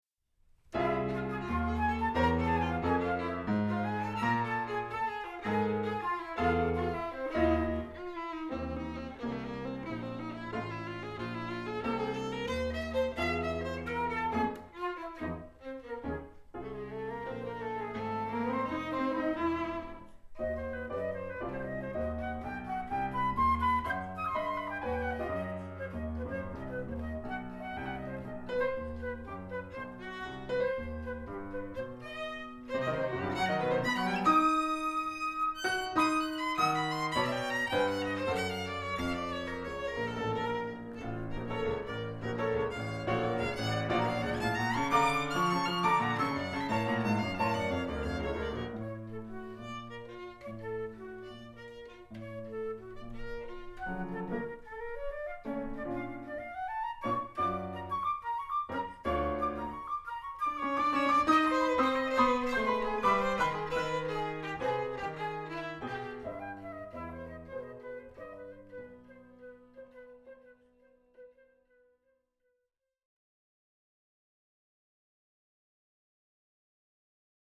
for flute/violin/piano trio (2010) [日本初演 / Japanese première]
場所：中目黒GTプラザホール